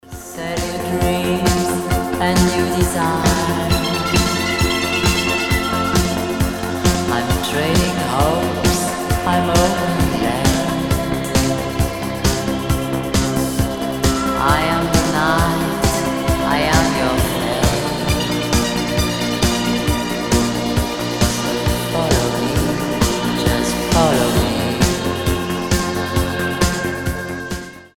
диско
europop
70-е